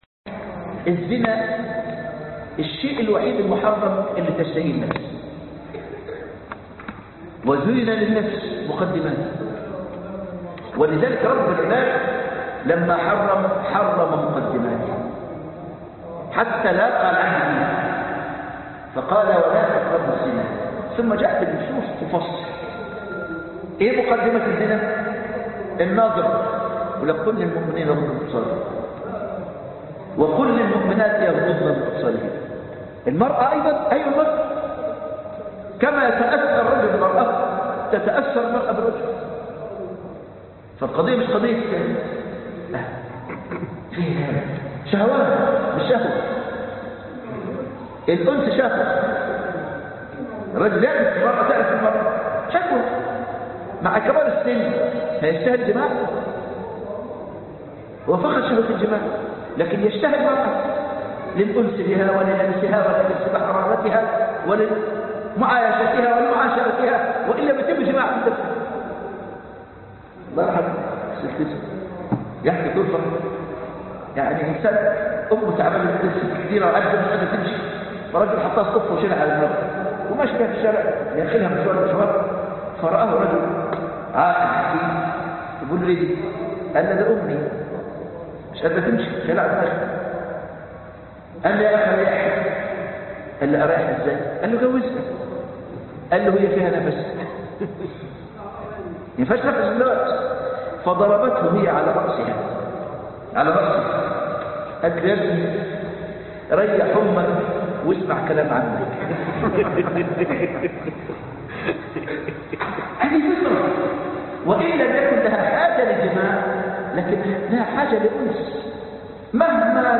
التعدد (مسجد العطية